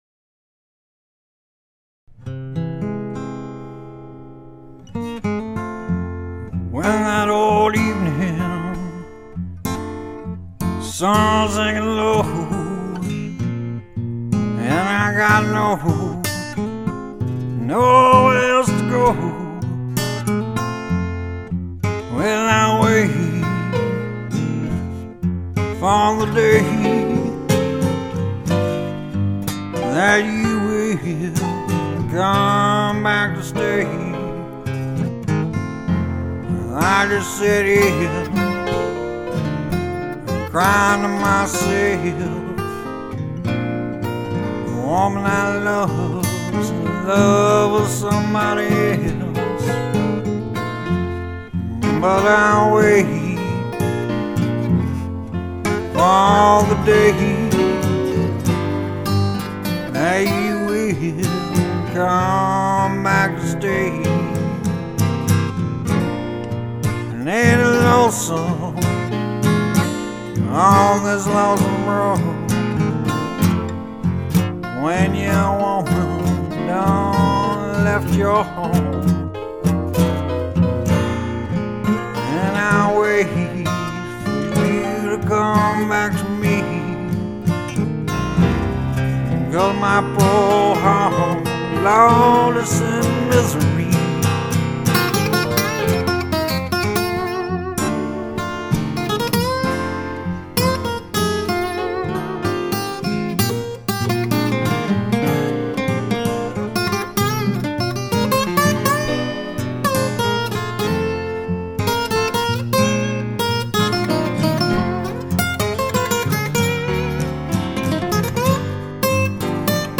Lead Guitar Written/Performed
Acoustic "Front Porch Blues" type of song